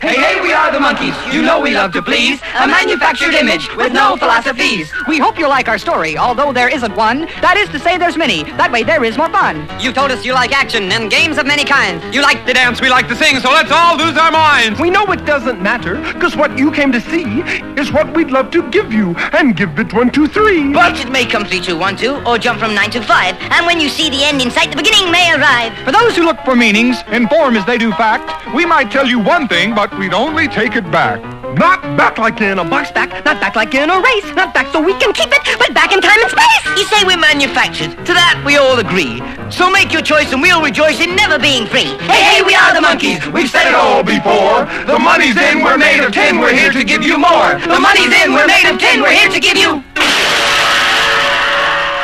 ROCK / 60'S / PSYCHEDELIC
67年の当時を思わせるサイケデリック色も強いアルバム！